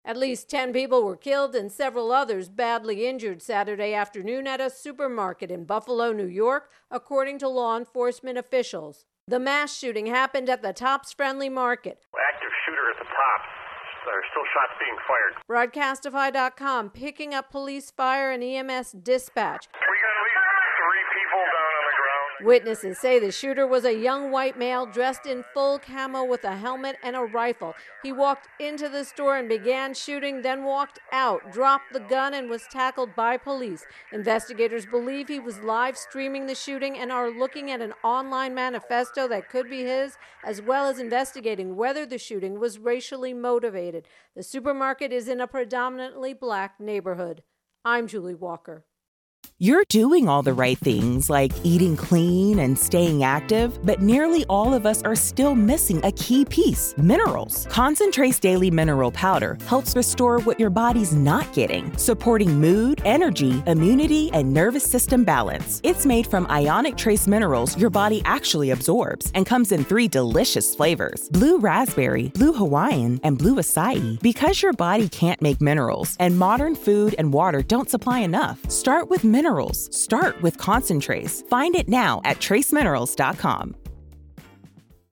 Buffalo Supermarket Shooting intro and voicer